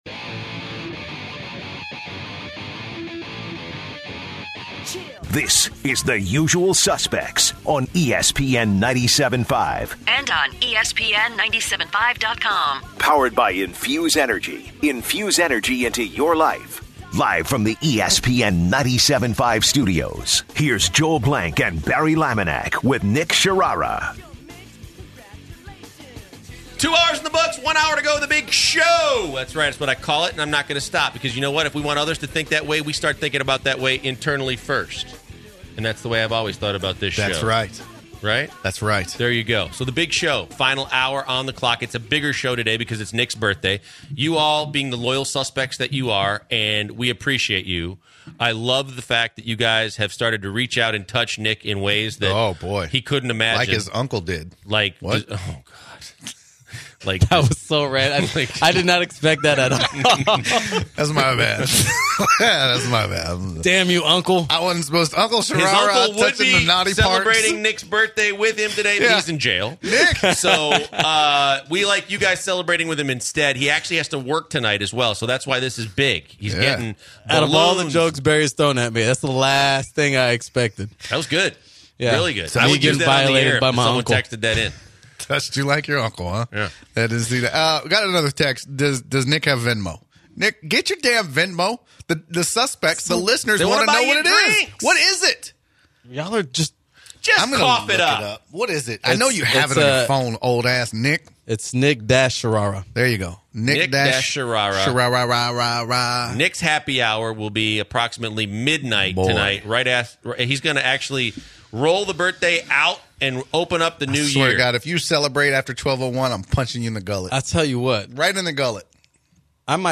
The last hour of the show kicks off with a Listener calling in to give the guys a tip on how to battle Directv’s signal lost when it rains. They discuss an American Entrepreneur Jenna Jameson who has had a major weight loss and who successfully promoted the Keto diet.